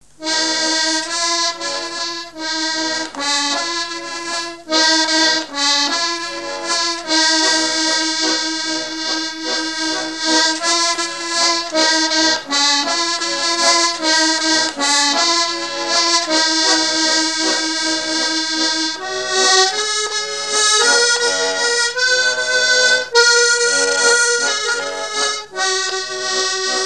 danse : valse
Pièce musicale inédite